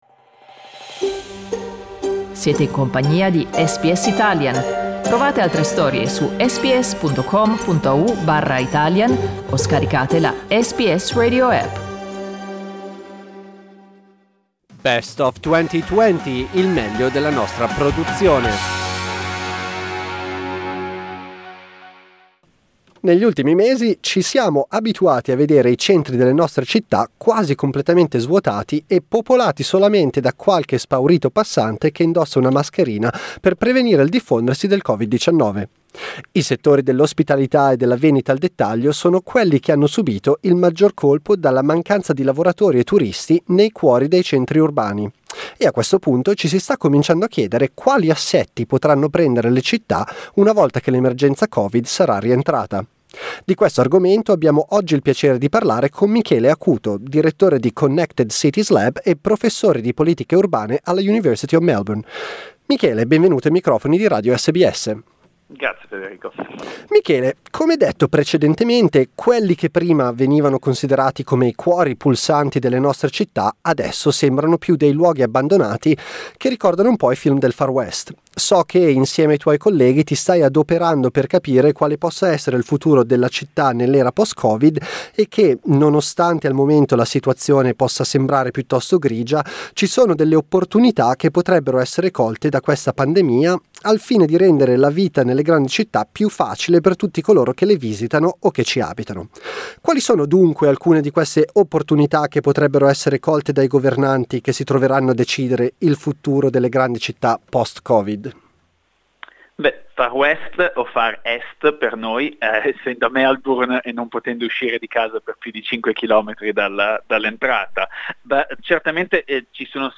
Vi riproponiamo un'intervista